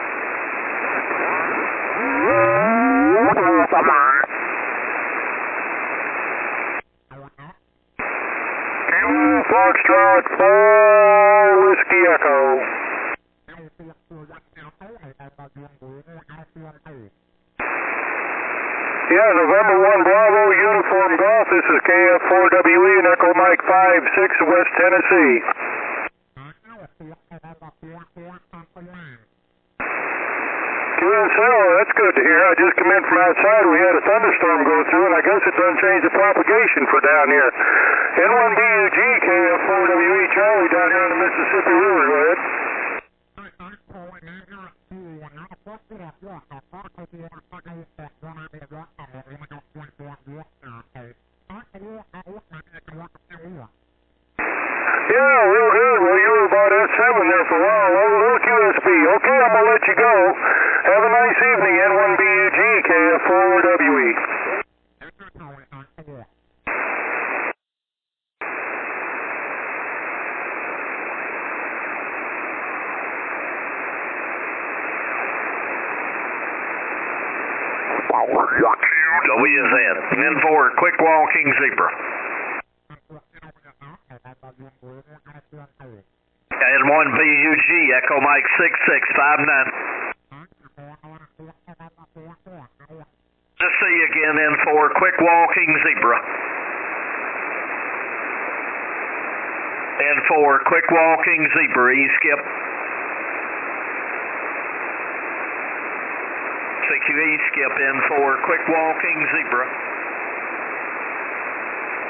Second 144 MHz Es opening in a week! Good loud signals from Tennessee.
Still running 25 watts and short 7 element yagi here.